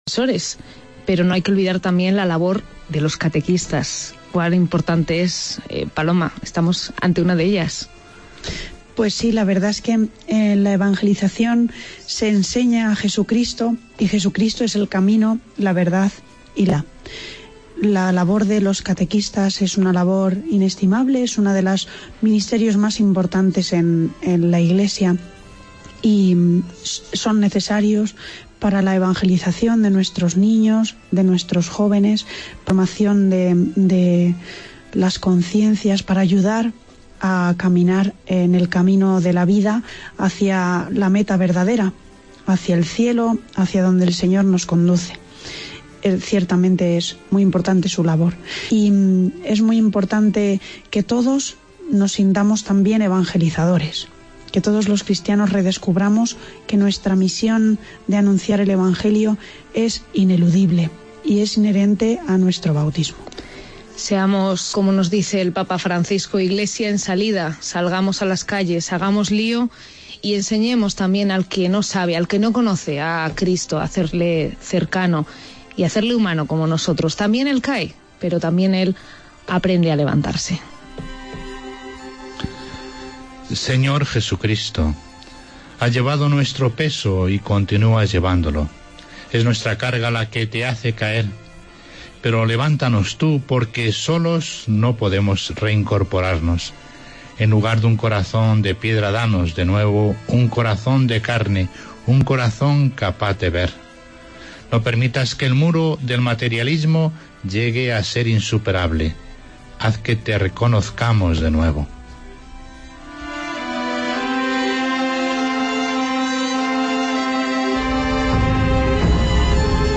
AUDIO: Vía Crucis de Penitencia desde Avila